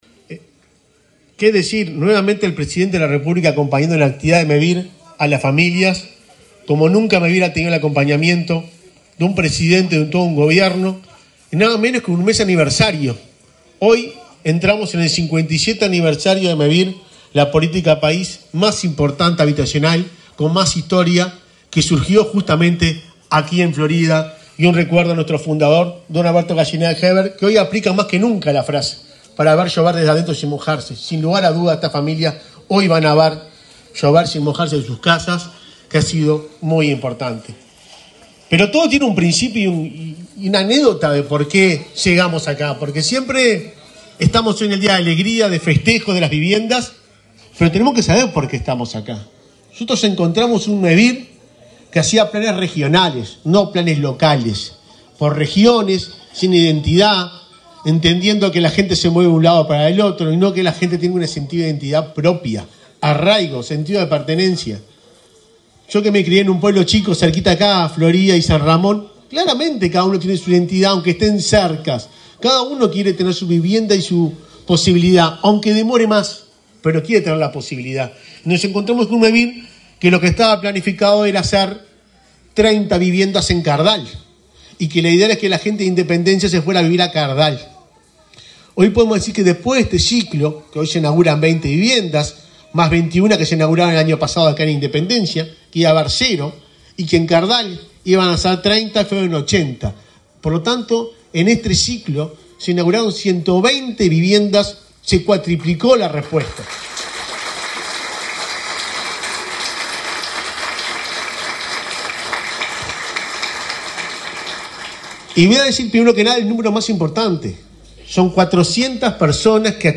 Palabras del presidente de Mevir, Juan Pablo Delgado
Palabras del presidente de Mevir, Juan Pablo Delgado 07/05/2024 Compartir Facebook X Copiar enlace WhatsApp LinkedIn El presidente de Mevir, Juan Pablo Delgado, participó, este martes 7 en Independencia, Florida, en la inauguración de un plan de viviendas, en esa localidad.